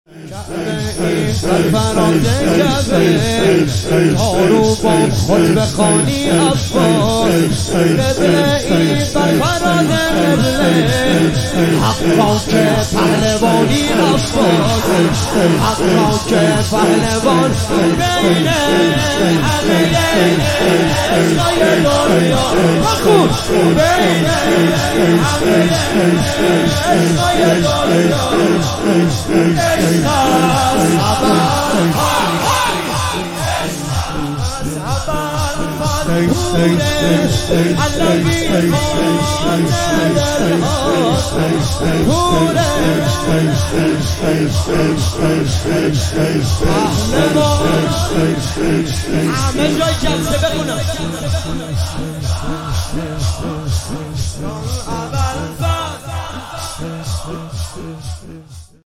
ایام زیارت مخصوصه امام رضا(ع) مسجد جامع اصفهان